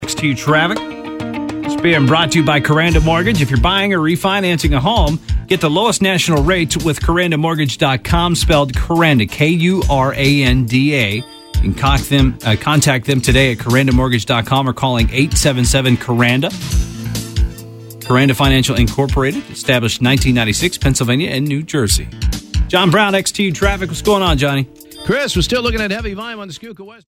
Radio Ad 34 - XTU
KurandaSpot_WXTU.mp3